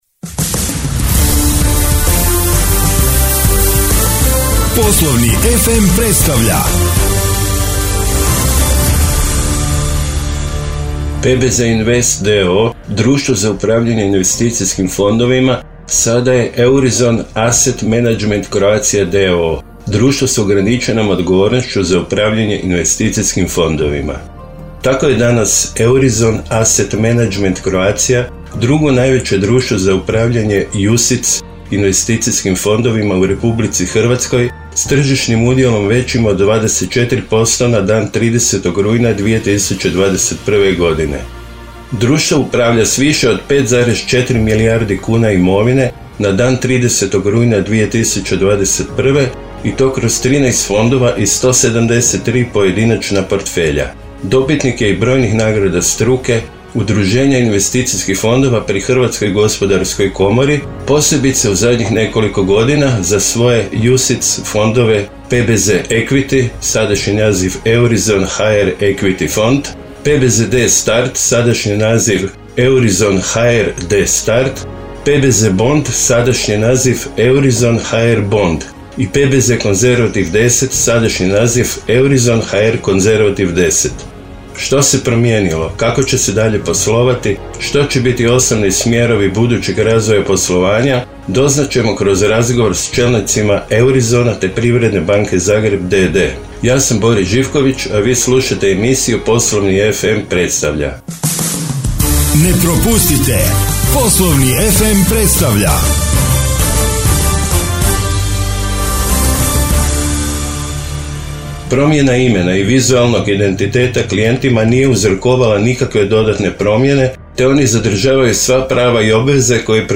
Što se promijenilo, kako će se dalje poslovati, što će biti osnovni smjerovi budućeg razvoja poslovanja, kroz razgovor s čelnicima Eurizona te Privredne banke Zagreb